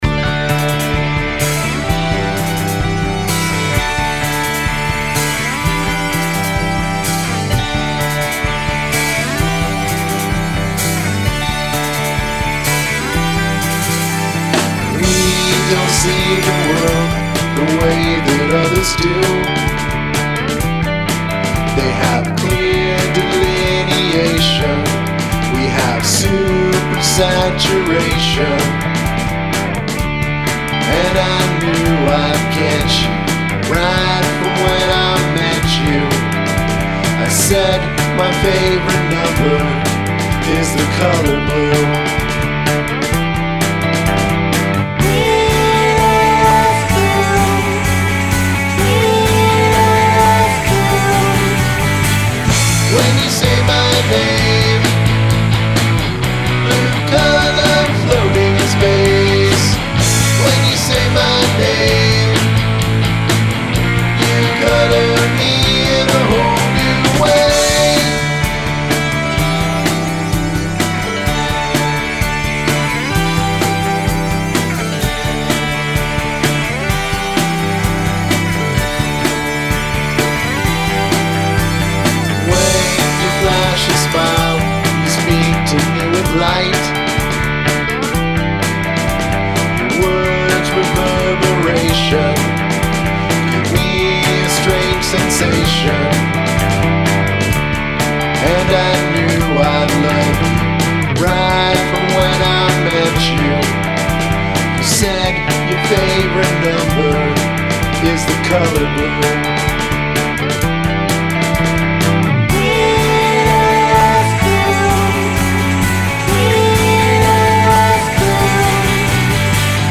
Sampled Vocal Hook
Love the headphone-candy panning stuff in the solo.